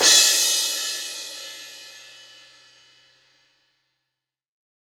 Q 15Crash.WAV